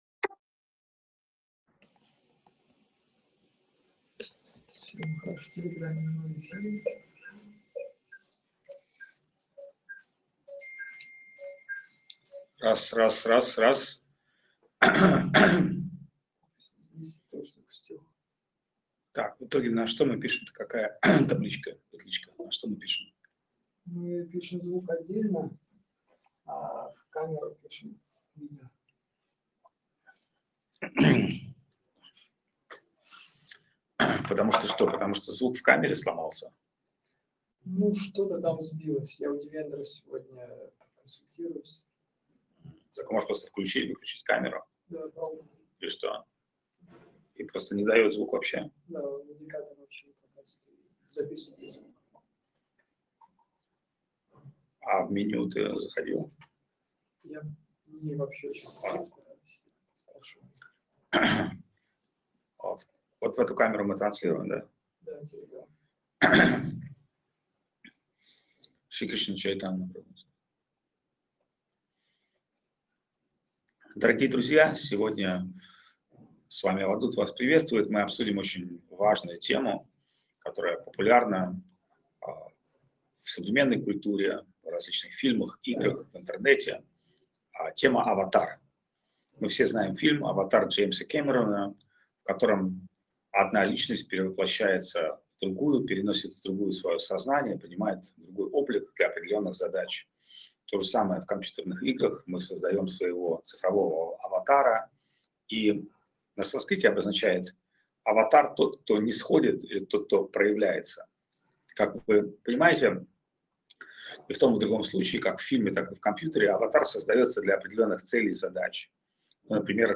Место: Чиангмай
Лекции полностью